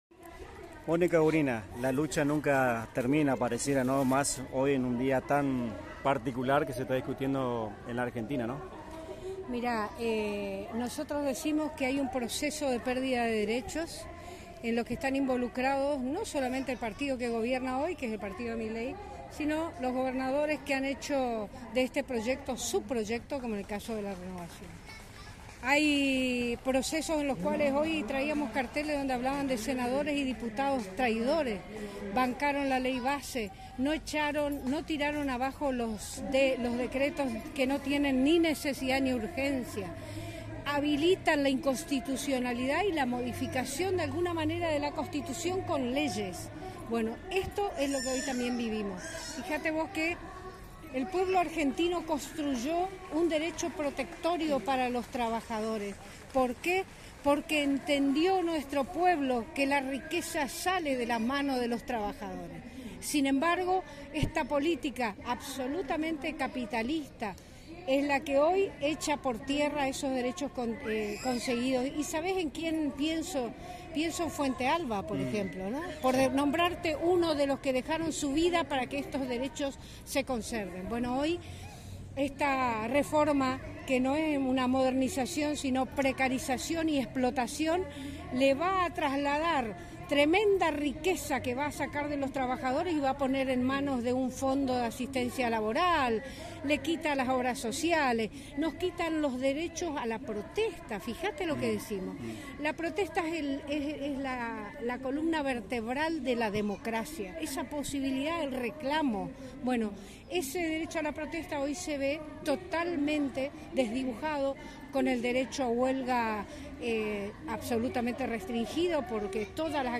Entrevistas
Aquí, el audio de la entrevista para Radio Tupambaé: